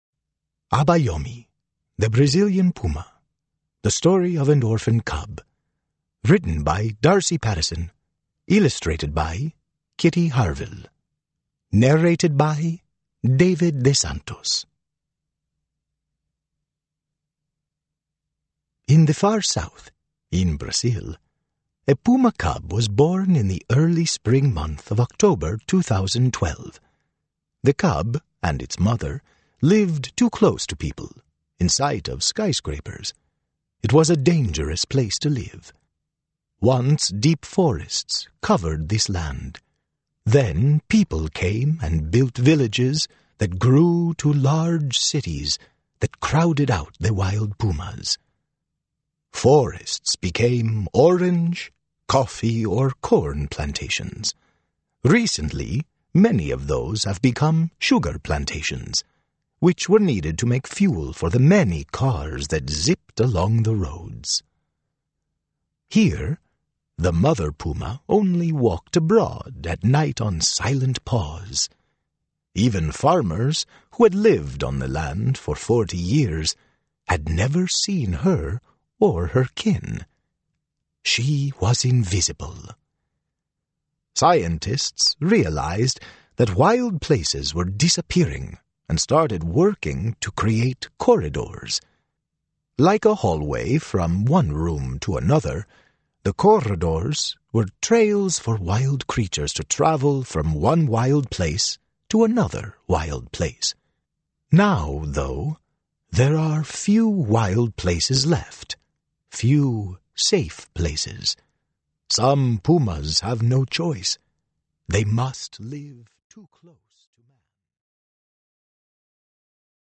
Audiobook - Abayomi, the Brazilian Puma